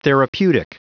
Prononciation du mot therapeutic en anglais (fichier audio)